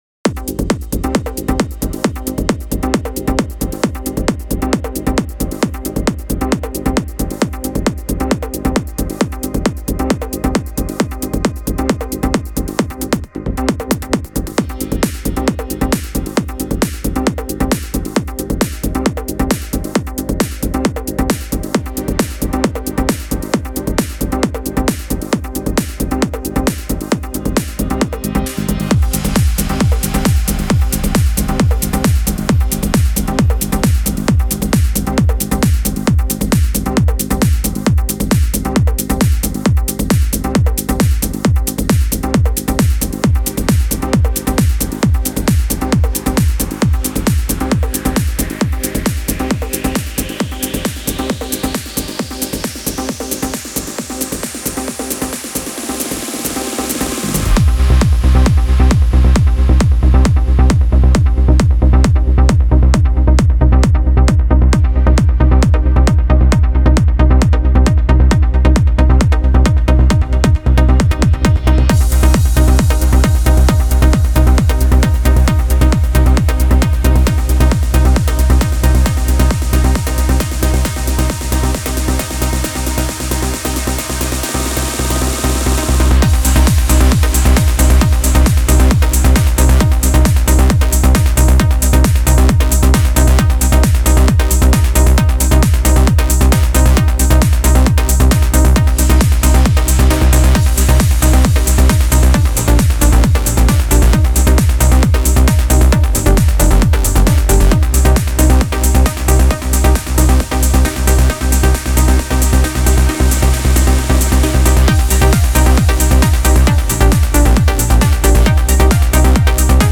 سبکشم عالیه ترنس